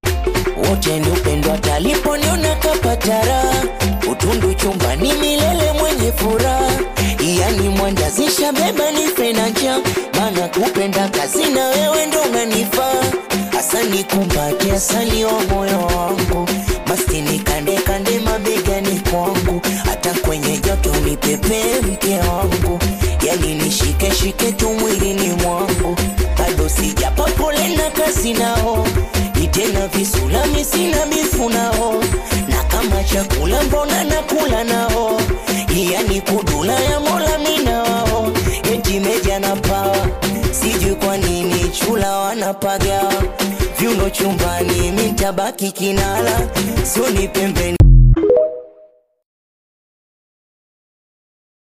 SINGELI
Singeli track